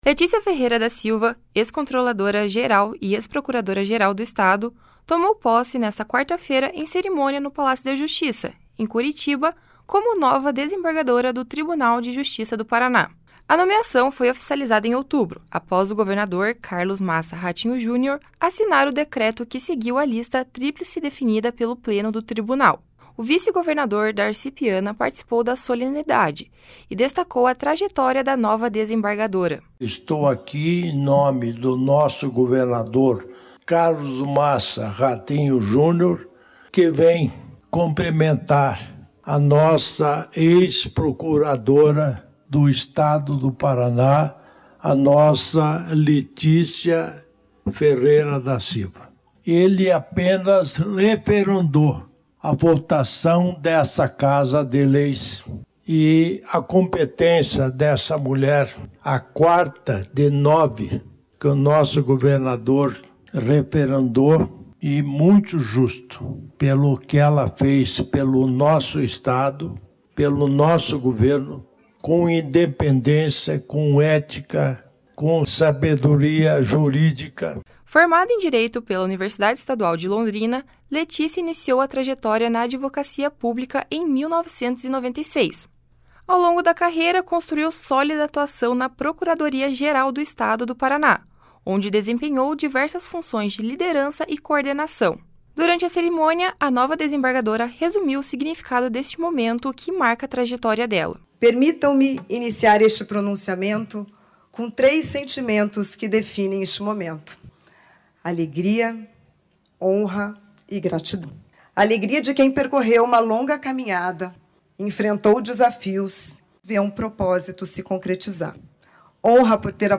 Leticia Ferreira da Silva, ex-controladora-geral e ex-procuradora-geral do Estado, tomou posse nesta quarta-feira, em cerimônia no Palácio da Justiça, em Curitiba, como nova desembargadora do Tribunal de Justiça do Paraná.
O vice-governador Darci Piana participou da solenidade e destacou a trajetória da nova desembargadora.
Durante a cerimônia, a nova desembargadora resumiu o significado desse momento que marca a trajetória dela.